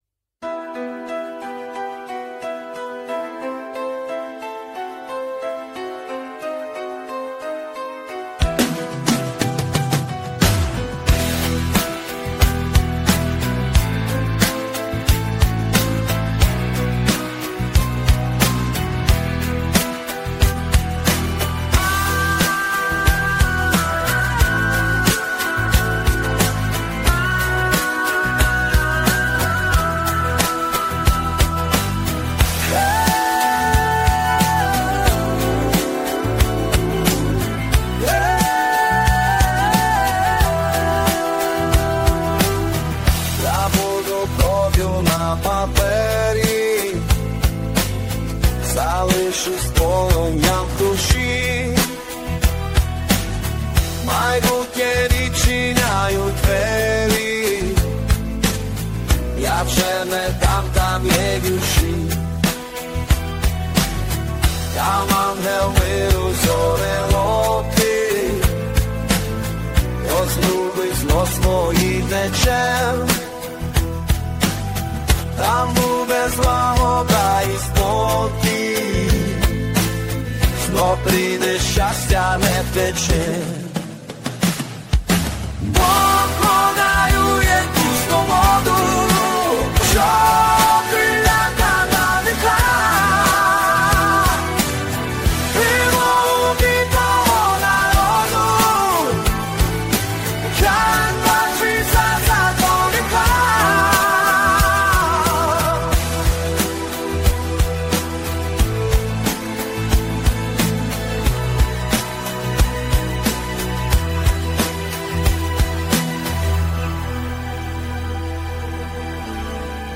Текст - автора, виконання пісні - ШІ
ТИП: Пісня
СТИЛЬОВІ ЖАНРИ: Ліричний